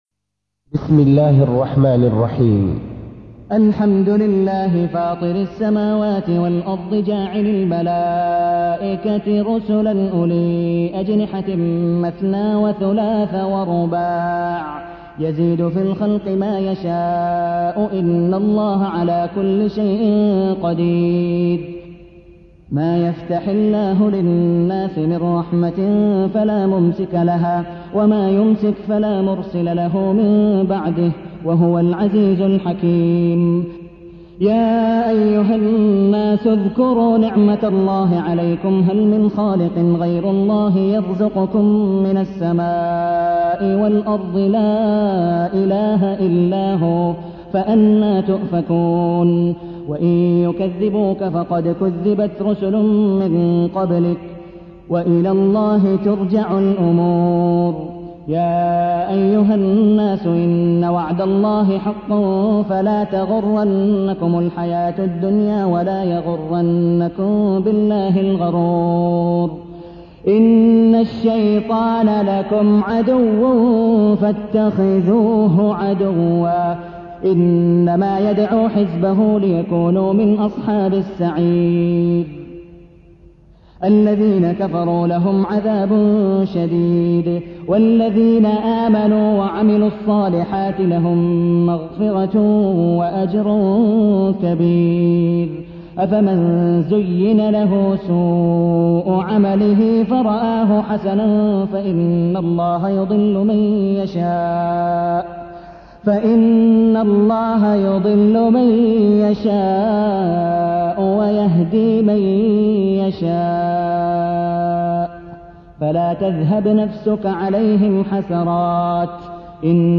تحميل : 35. سورة فاطر / القارئ عبد الودود مقبول حنيف / القرآن الكريم / موقع يا حسين